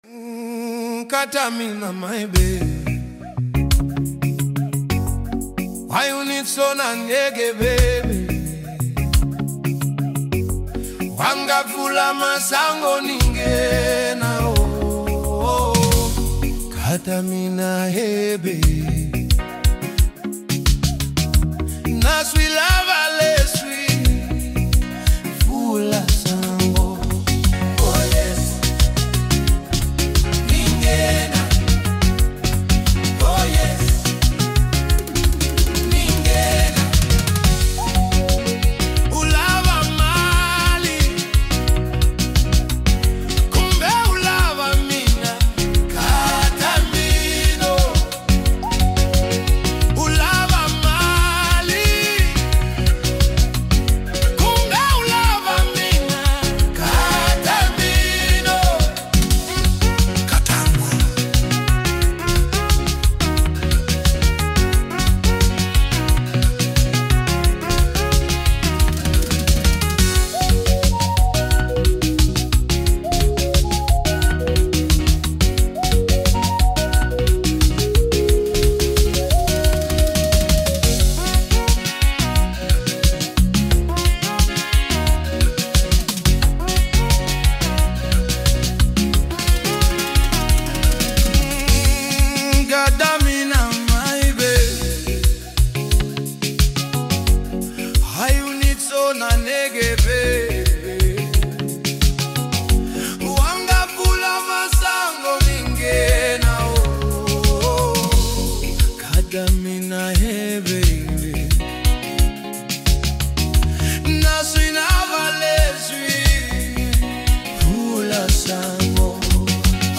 Home » Amapiano » DJ Mix » Gqom » Lekompo